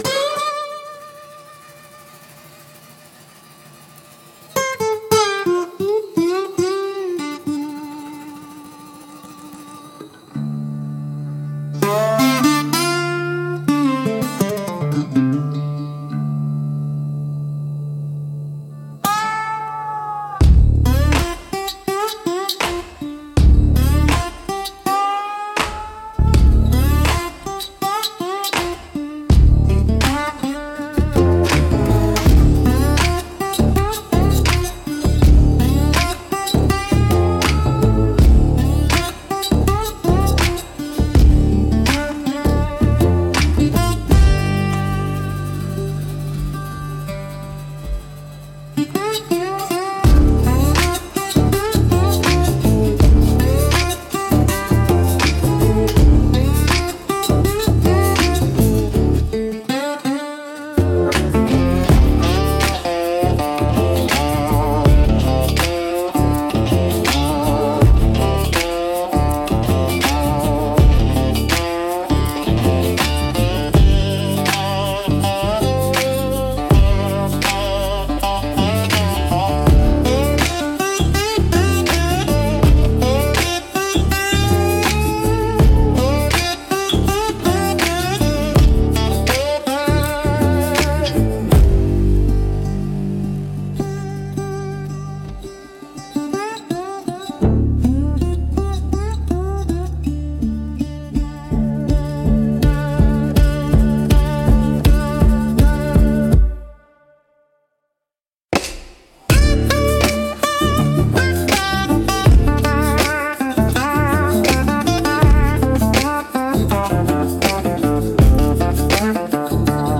Instrumental - Whiskey for the Wicked 2.33